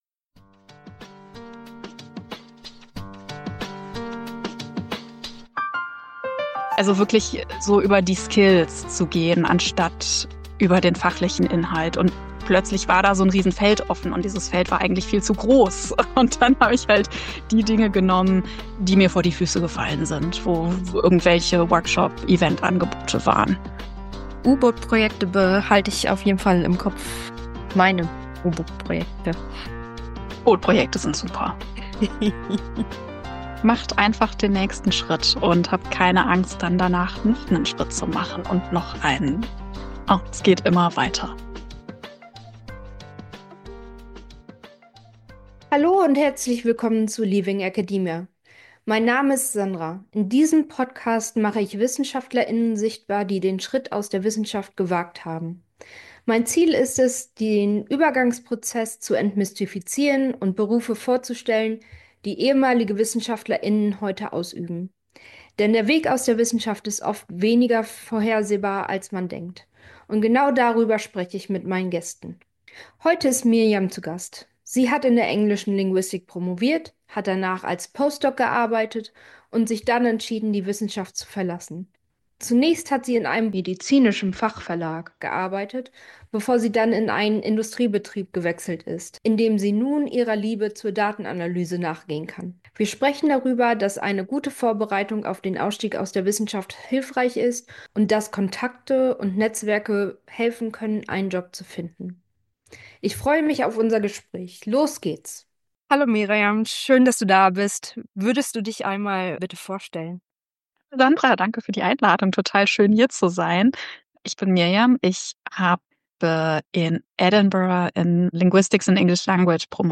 Deshalb war es für mich auch eine wunderbare Gelegenheit, wieder einmal ein längeres Gespräch zu führen und unserem Gespräch merkt man glaub ich auch an, dass wir miteinander vertraut sind.